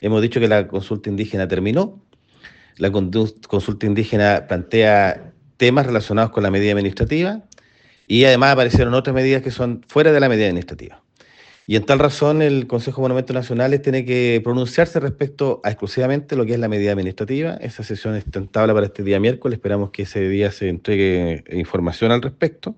En conversación con La Radio, el delegado Alvial reveló que el Consejo de Monumentos Nacionales sesionará el miércoles para definir cómo se ejecutará el resguardo de los restos de cerámica mapuche encontrados mientras comenzaban las faenas del hospital.